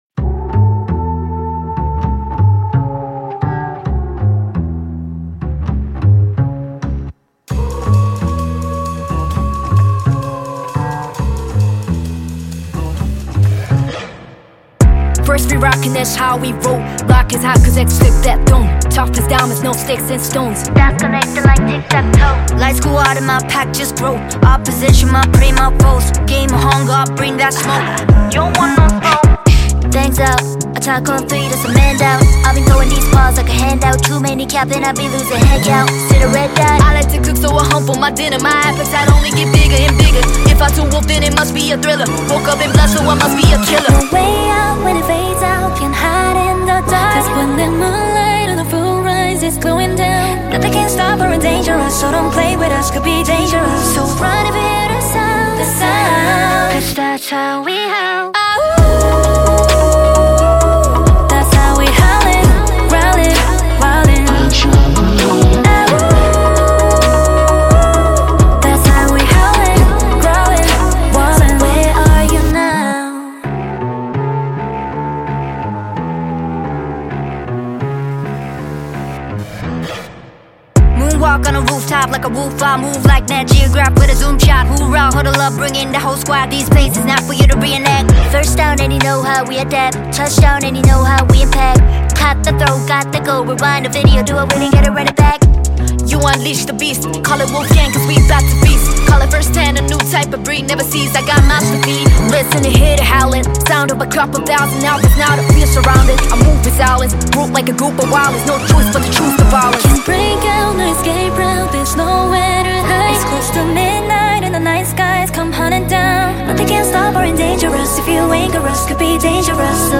Label Dance